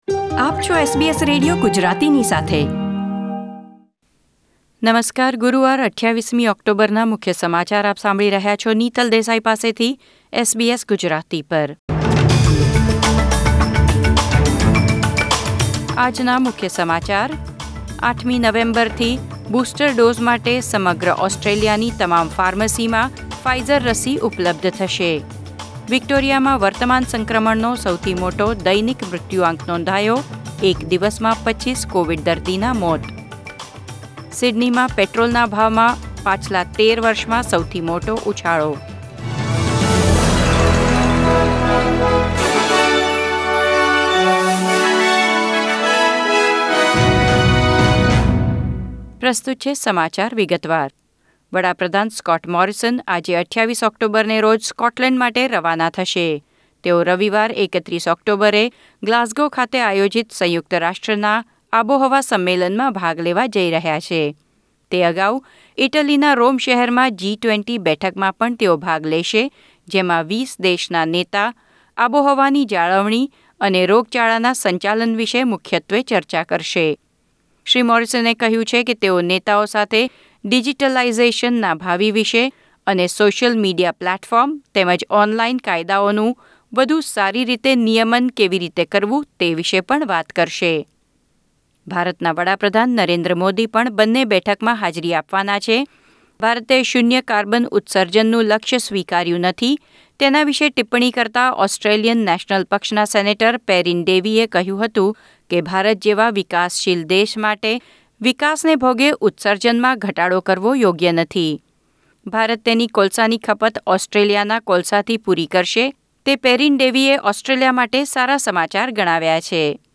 SBS Gujarati News Bulletin 28 October 2021